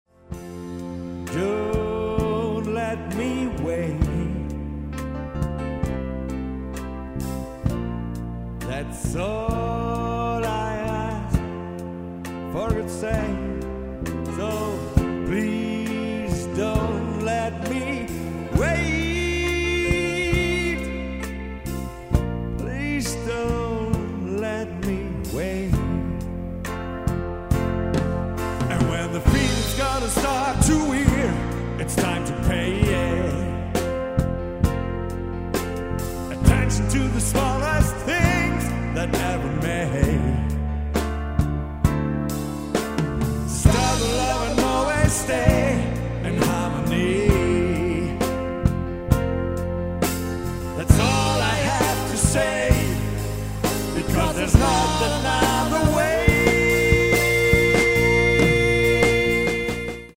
Singetrack